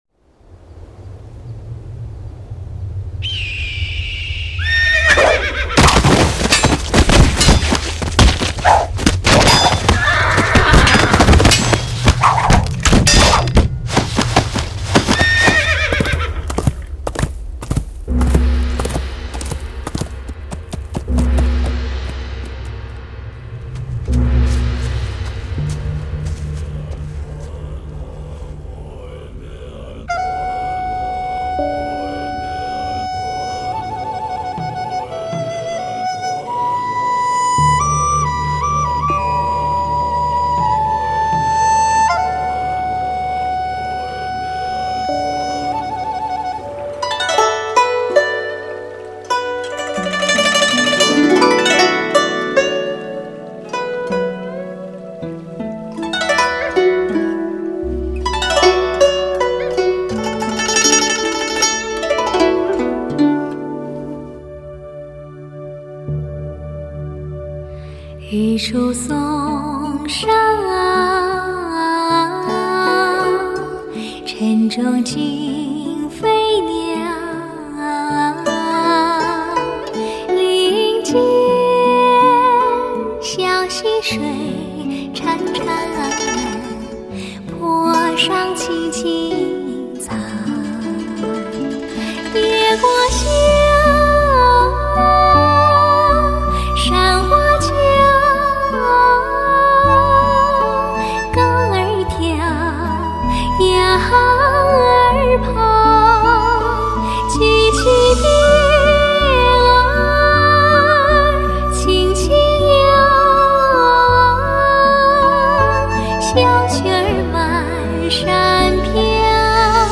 发烧试音新标准，年度压轴女声至尊！
首度集结，精挑细选，示范录音，金牌女声！